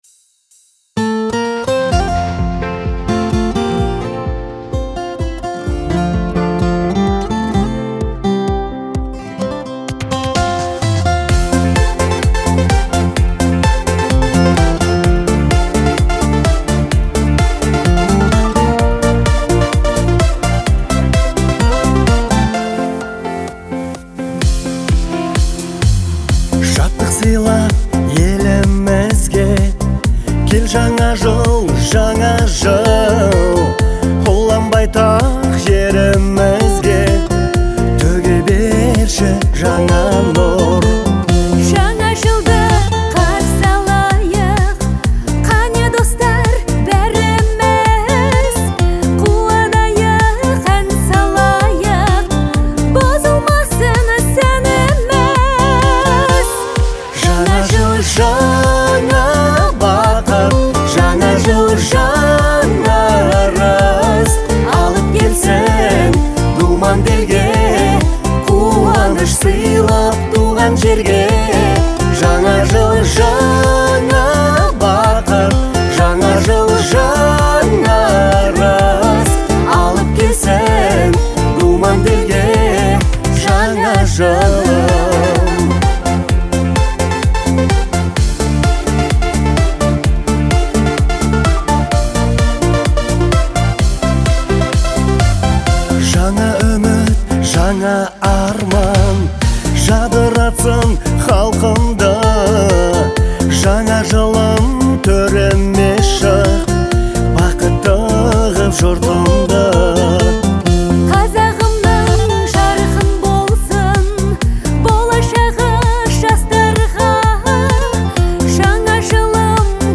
это яркая и праздничная песня, исполненная дуэтом